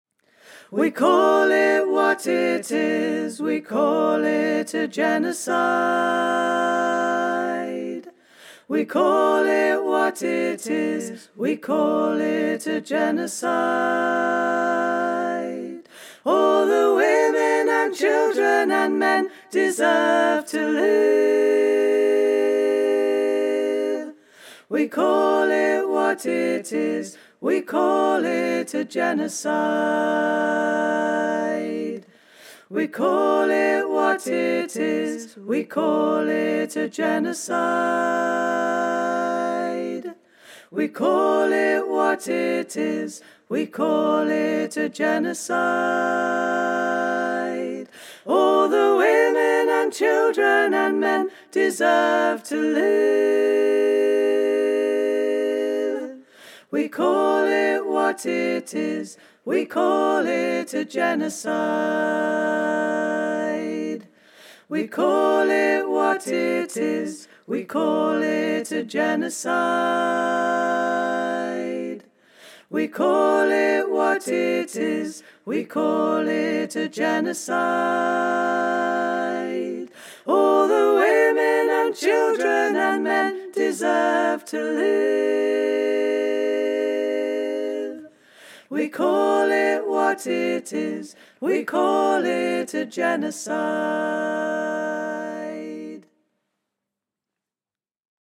A street song